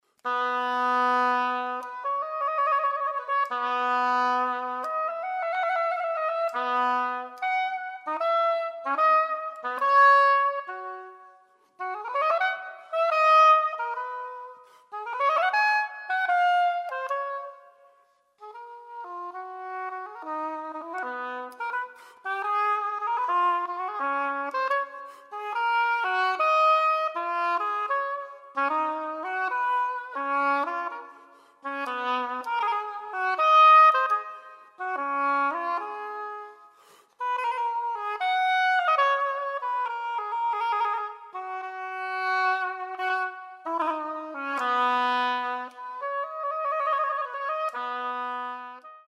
baroque oboe